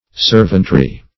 Servantry \Serv"ant*ry\, n. A body of servants; servants, collectively.